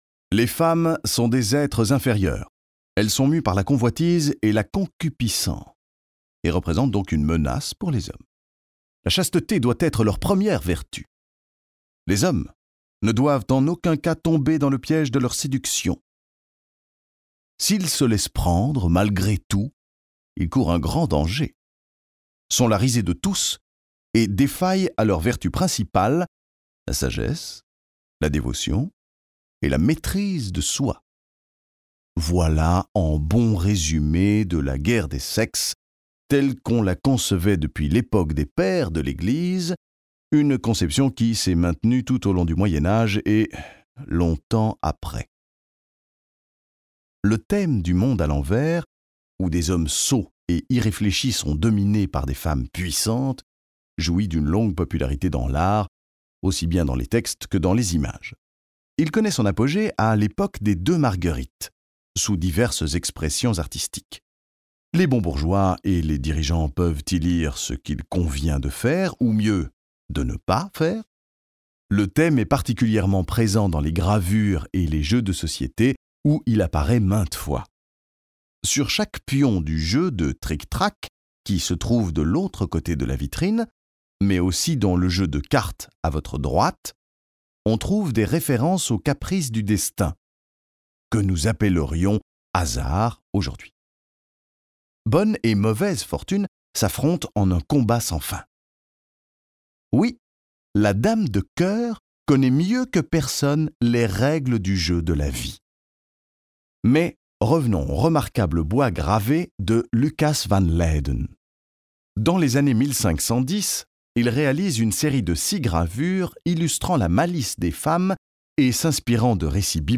Tief, Natürlich, Erwachsene, Warm, Sanft
Audioguide